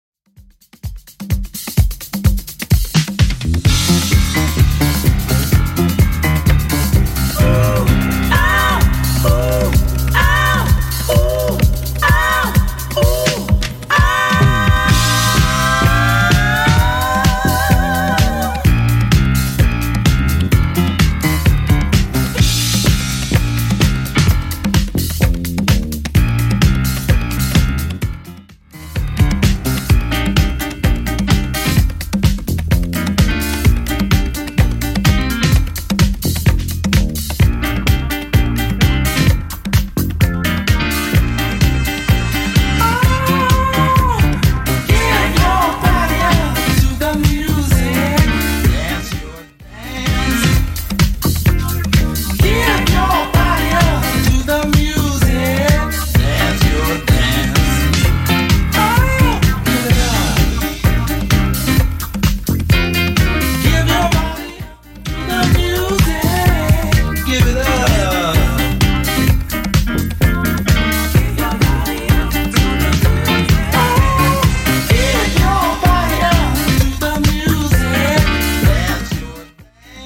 BPM: 128 Time